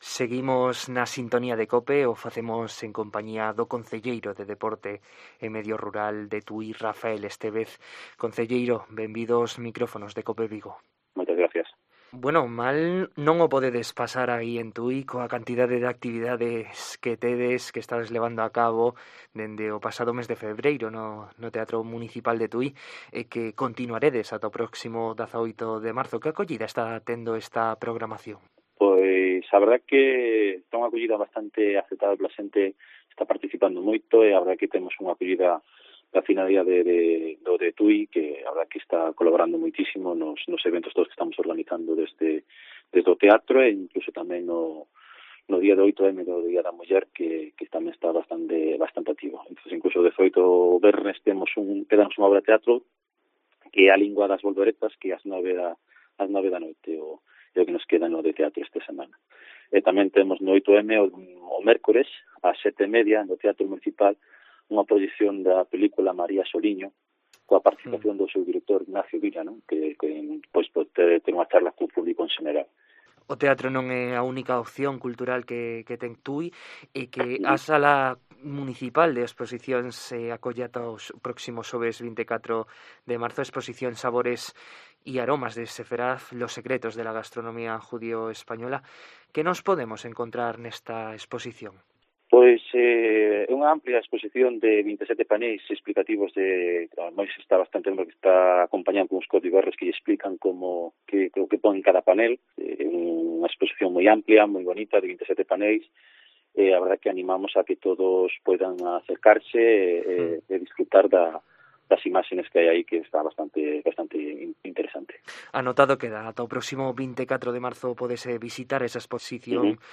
Entrevista a Rafael Estévez, concelleiro de Deporte y Medio Rural del Concello de Tui